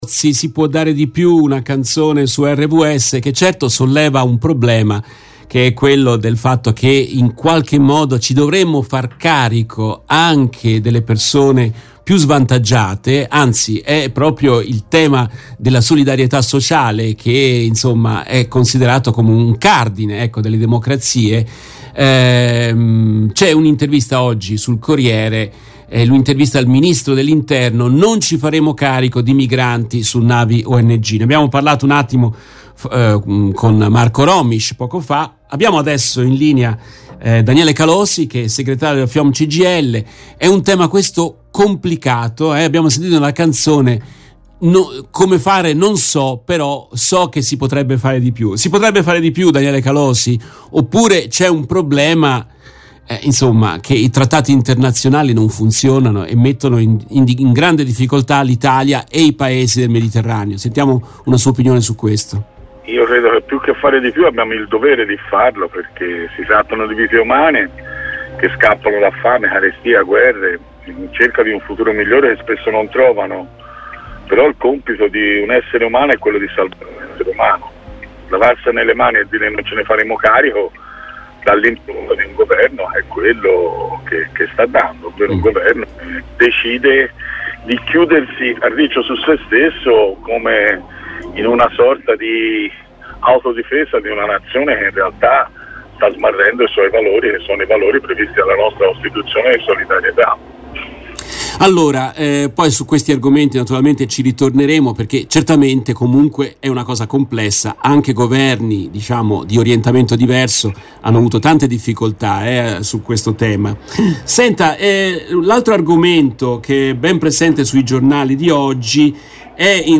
In questa intervista tratta dalla diretta RVS del 02 novembre 2022